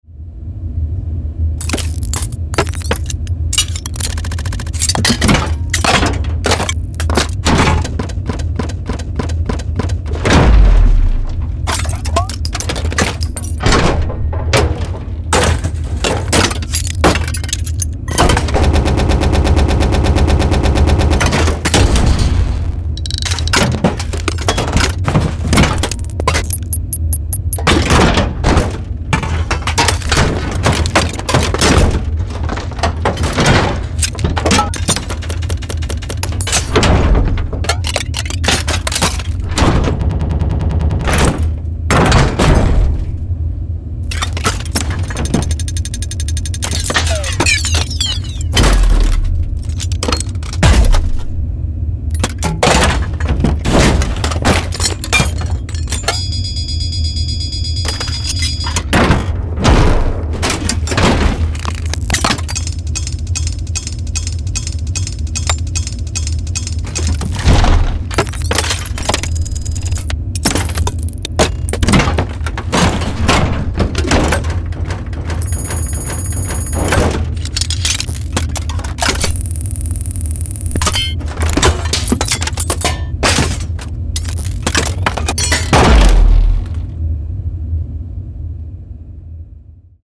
Steampunk Mechanical Sound Effects
Get retro-futuristic mechanical sound effects of factory machinery and industrial mechanisms.